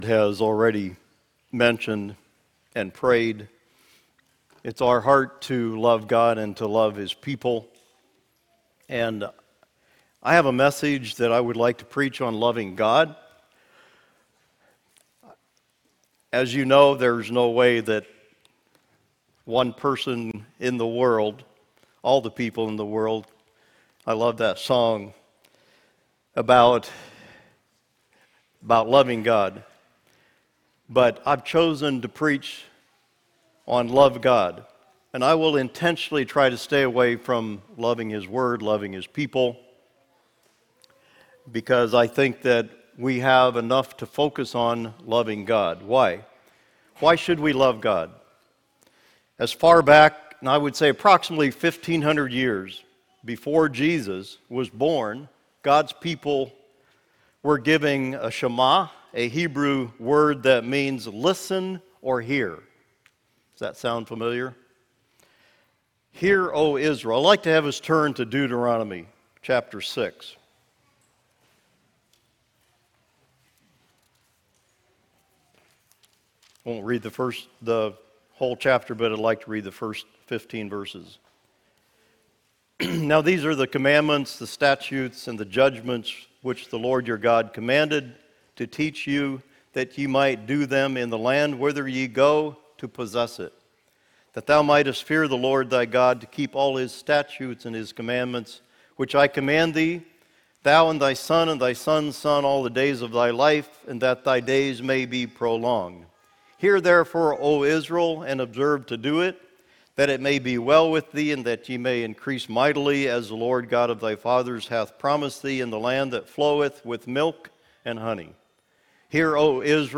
Sermon Archive | - New Covenant Mennonite Fellowship